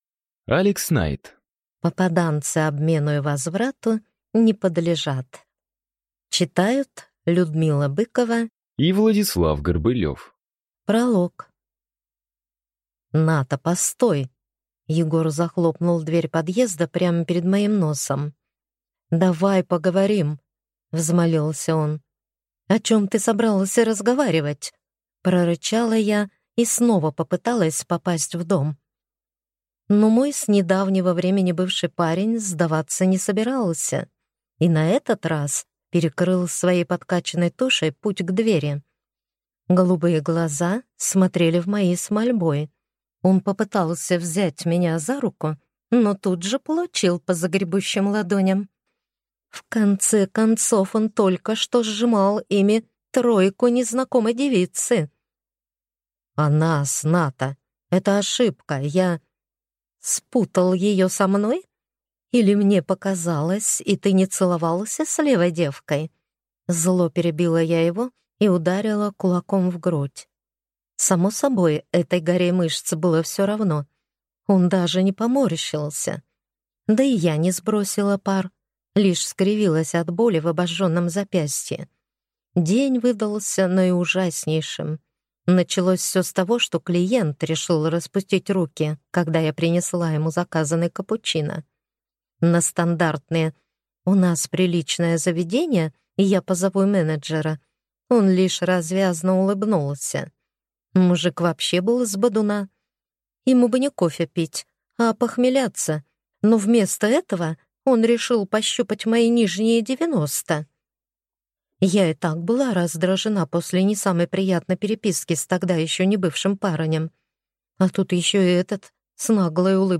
Аудиокнига Попаданцы обмену и возврату не подлежат | Библиотека аудиокниг